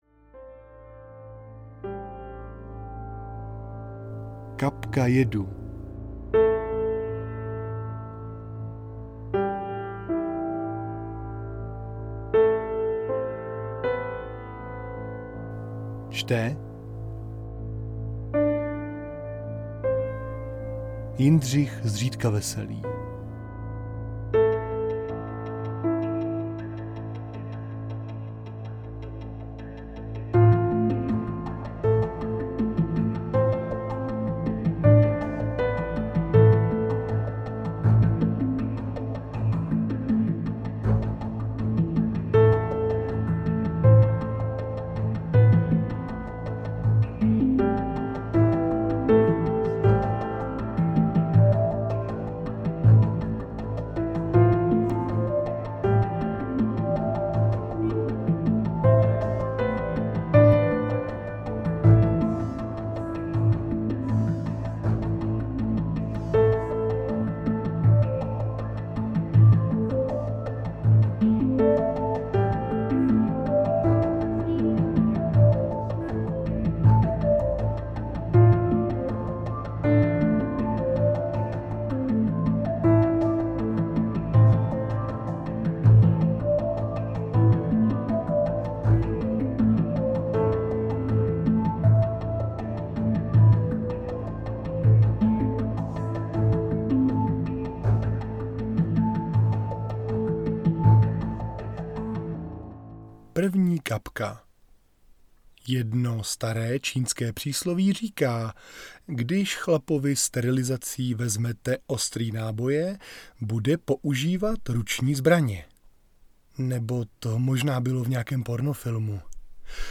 Kapka jedu audiokniha
Ukázka z knihy